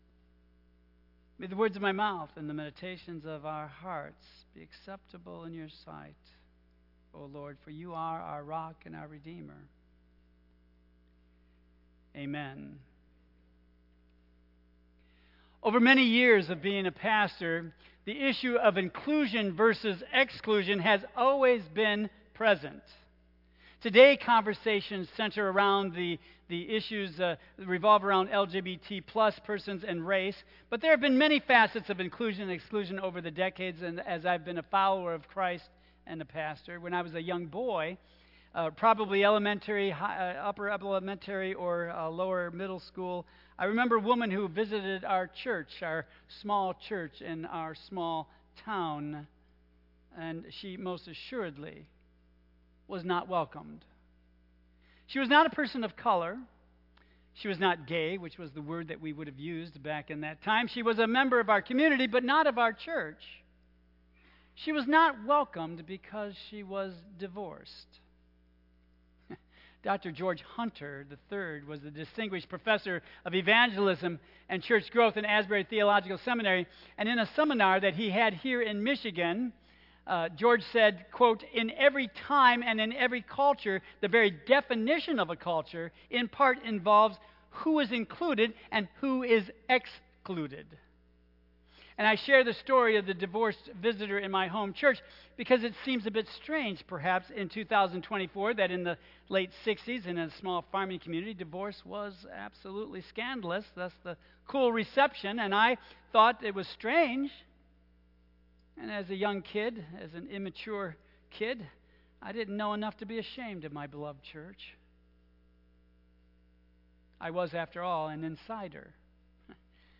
Tagged with Michigan , Sermon , Waterford Central United Methodist Church , Worship Audio (MP3) 7 MB Previous Our Mission: Changing Our World with Christ's Love Next Small Steps Towards Big Change